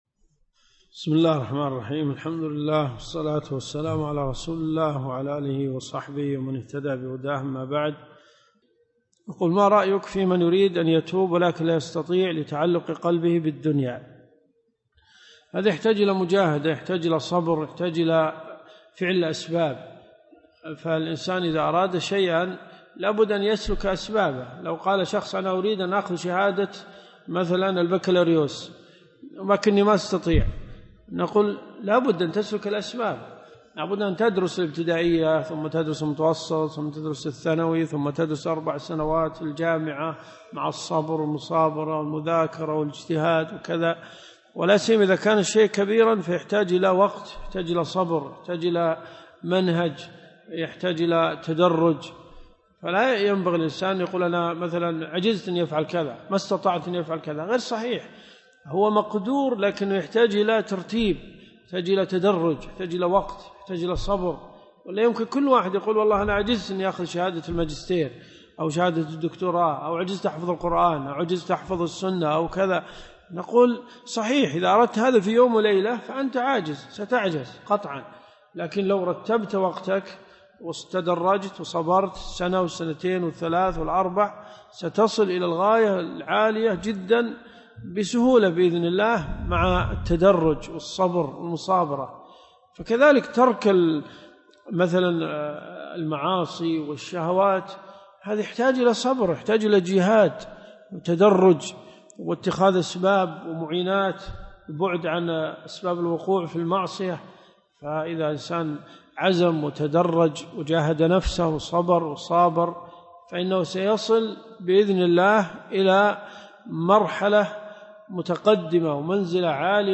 المدينة المنورة . جامع البلوي